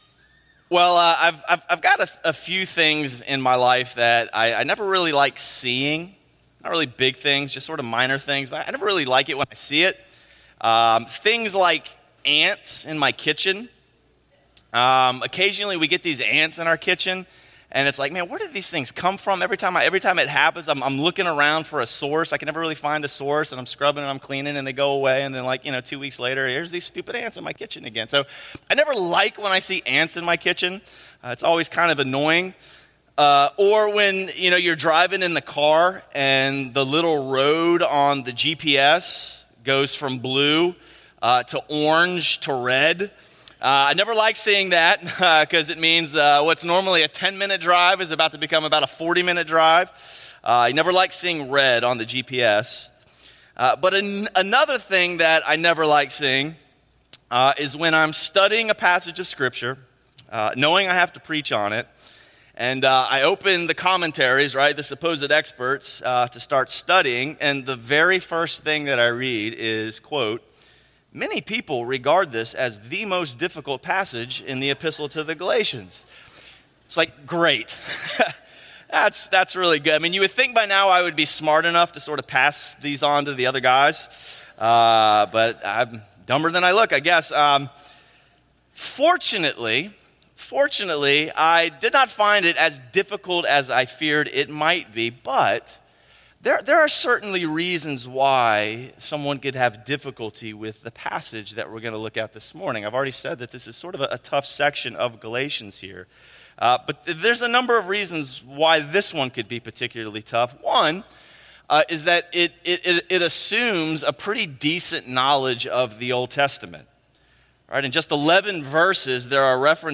A sermon series on Galatians by Crossway Community Church in Charlotte, NC.